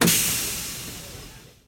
doors.ogg